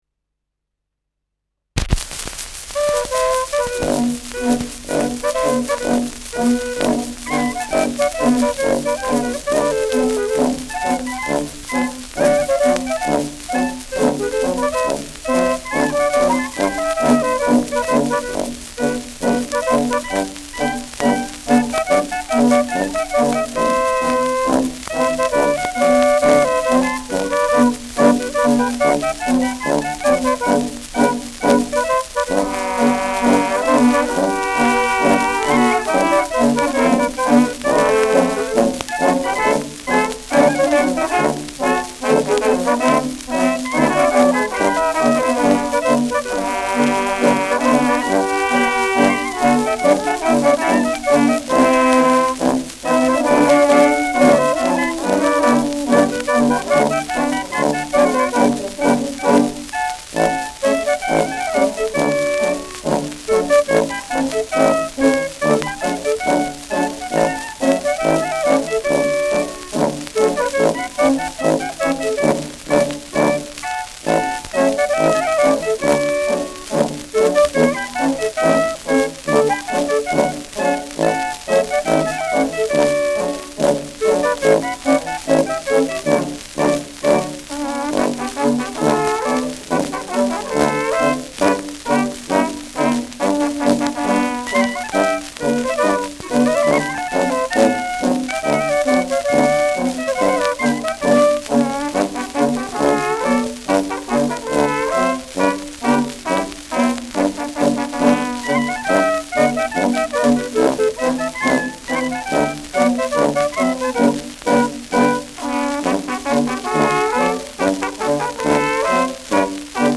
Schellackplatte
präsentes Rauschen
Kapelle Feuerhäusla, Hof (Interpretation)